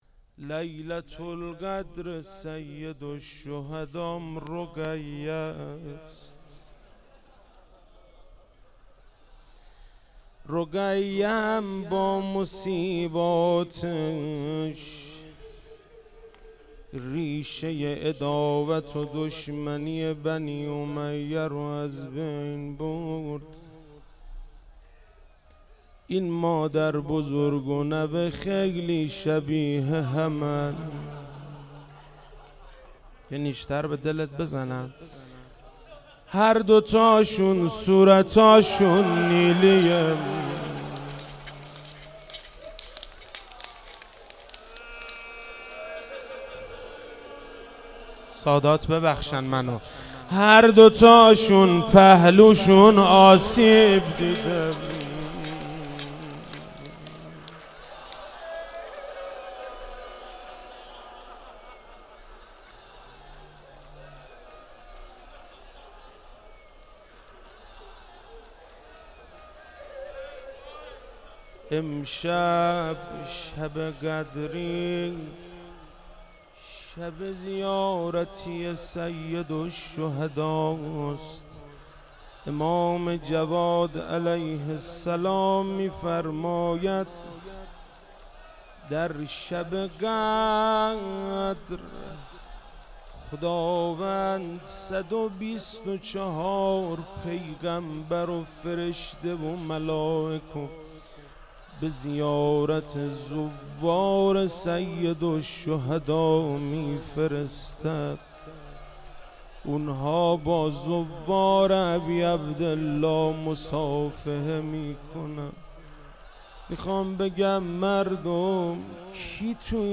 شب 23 ماه مبارک رمضان 95(قدر)_روضه_حضرت رقیه سلام الله علیها
روضه